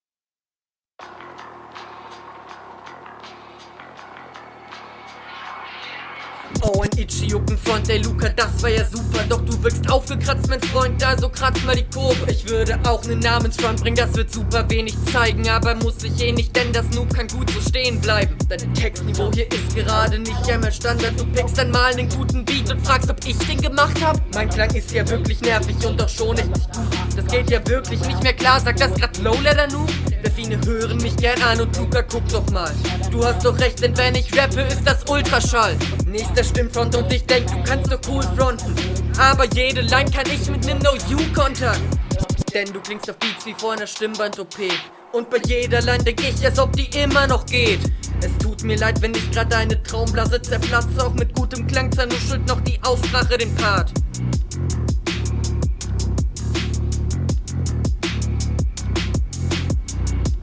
Mische schwächer, Style eher generic und flow in Ordnung aber nicht das Highlight.